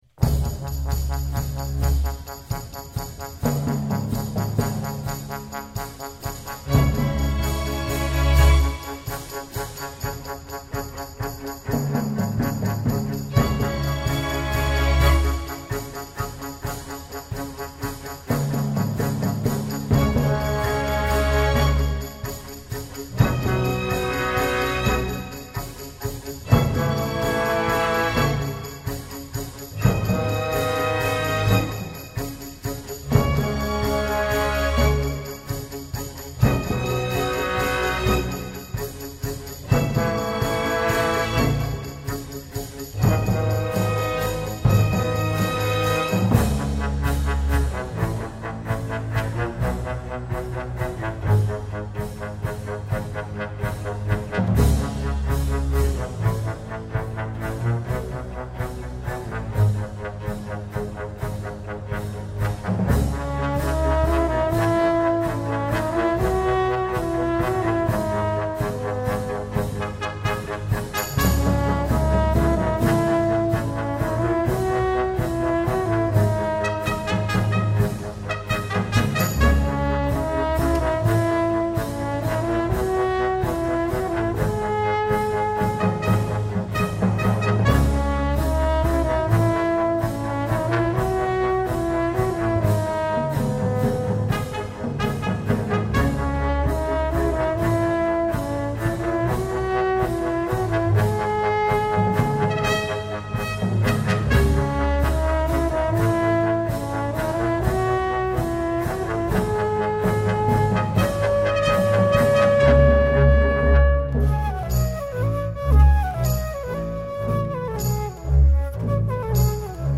συμφωνικά έργα